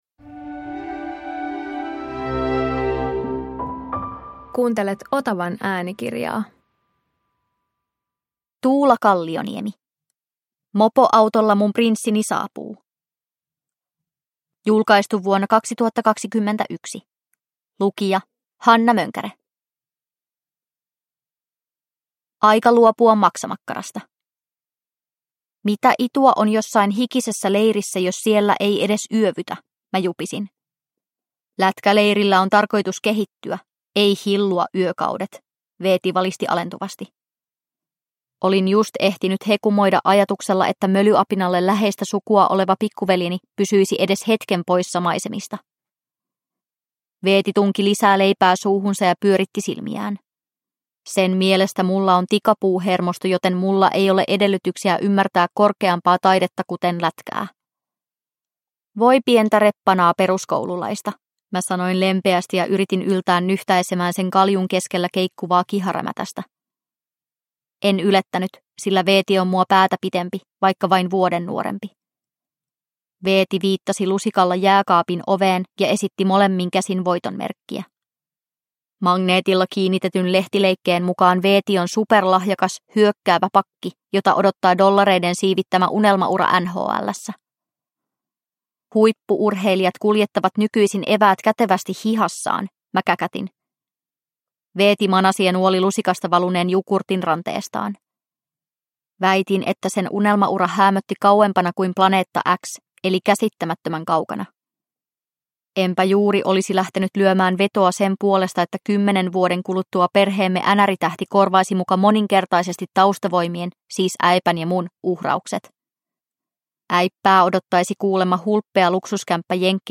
Mopoautolla mun prinssini saapuu – Ljudbok – Laddas ner